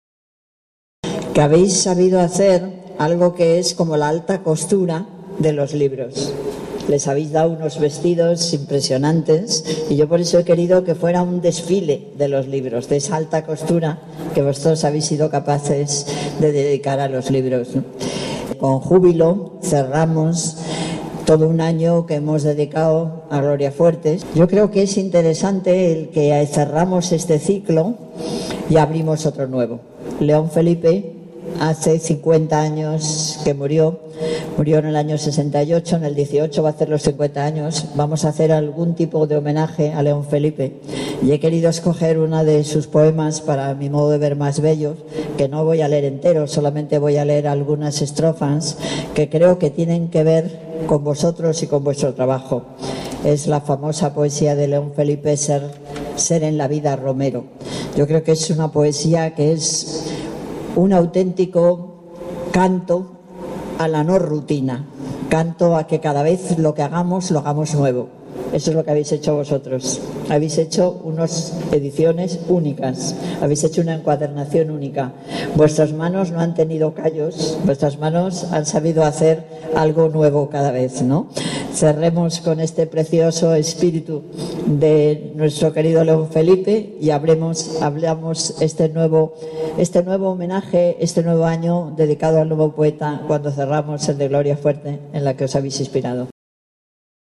Al hacer entrega de los galardones, la alcaldesa ha resaltado que estos premios son el colofón a los actos de homenaje realizados este año por el Ayuntamiento de Madrid en honor a Gloria Fuertes. Al mismo tiempo, ha anunciado su intención de que 2018 esté dedicado al poeta León Felipe, al cumplirse el 50º aniversario de su muerte en México, del que ha leido parte del poema Romero sólo.